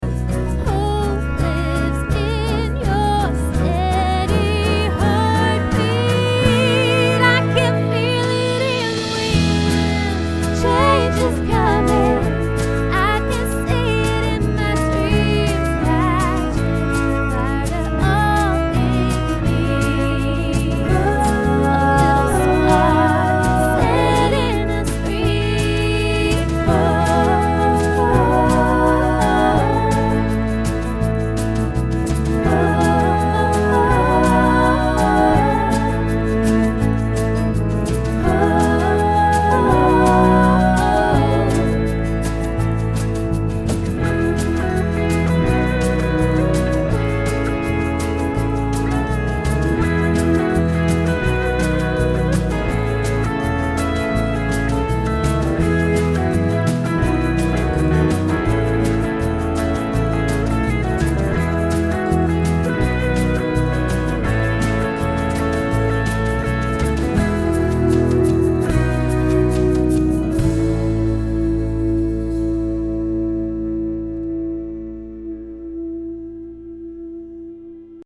unmixed multi-track: